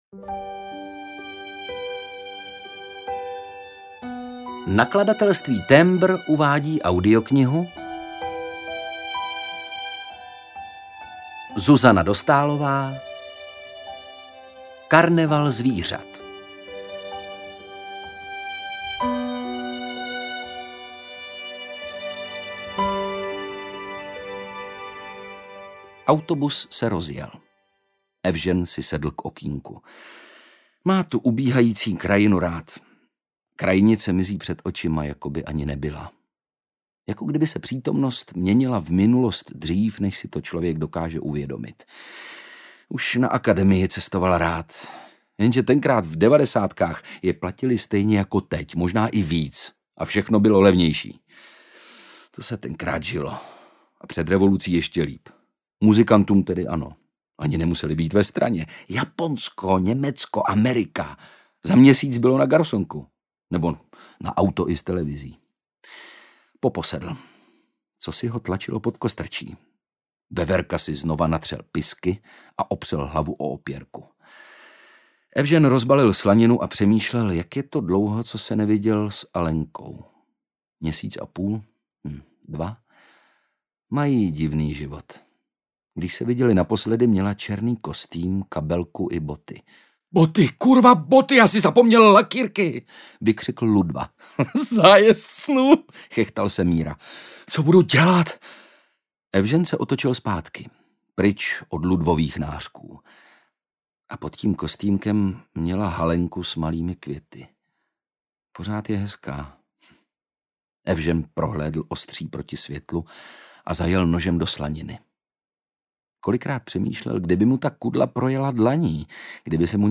Karneval zvířat audiokniha
Ukázka z knihy
Čte Ondřej Brousek
Natočeno ve studiu All Senses Production s. r.o.
• InterpretOndřej Brousek